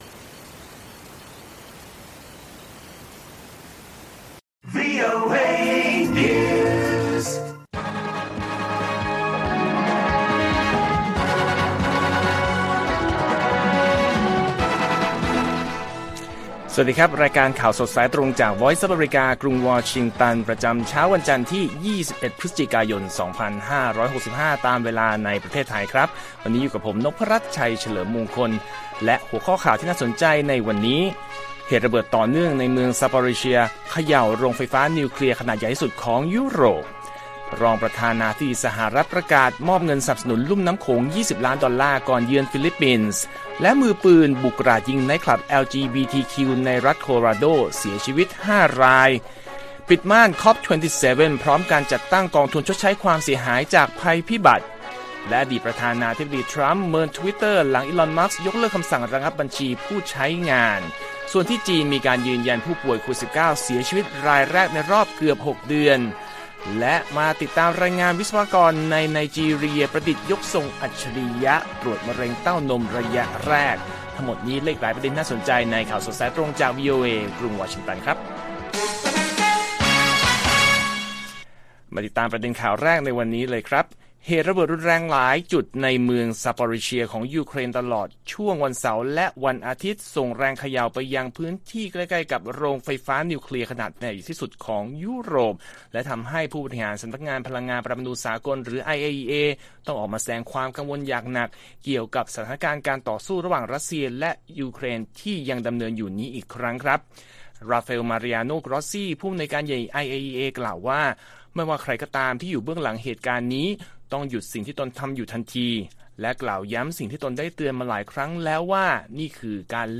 ข่าวสดสายตรงจากวีโอเอไทย วันจันทร์ ที่ 21 พ.ย. 2565